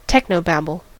technobabble: Wikimedia Commons US English Pronunciations
En-us-technobabble.WAV